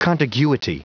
Prononciation du mot contiguity en anglais (fichier audio)
Prononciation du mot : contiguity